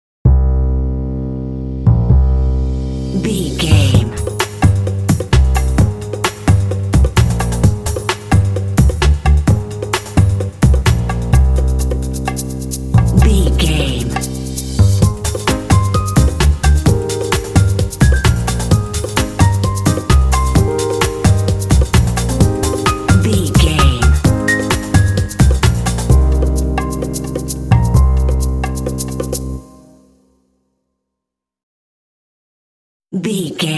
Aeolian/Minor
groovy
optimistic
percussion
bass guitar
piano
jazz